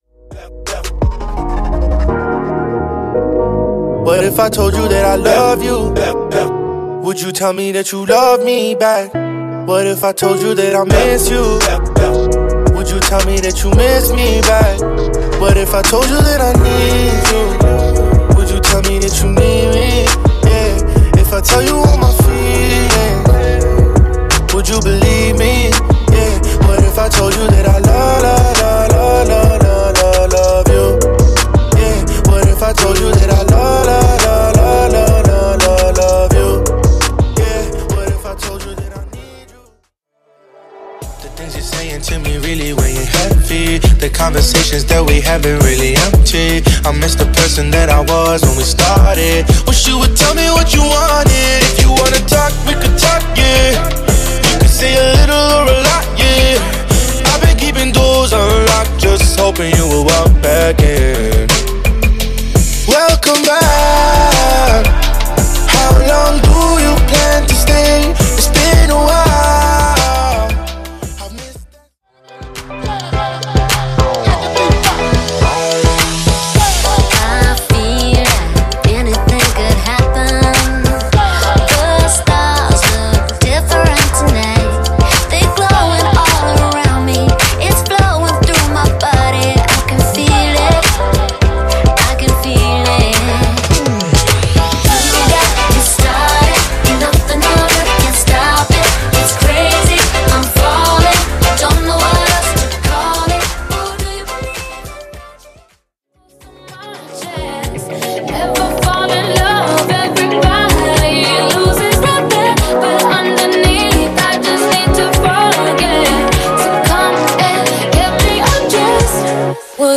• (Audio & Video Editor) Open Format Dj